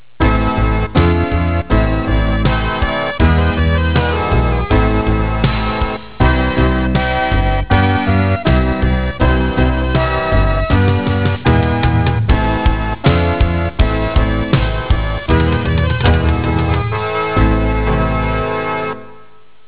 朝のことは知りませんが、夕方集まってくる雀の数の多かったこと200〜500羽、あるいは千羽？
何千何百の鈴を振り続けるような音といったらご理解いただけるでしょうか。
suzumesky.au